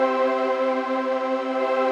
SaS_MovingPad05_125-C.wav